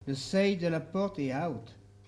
Catégorie Locution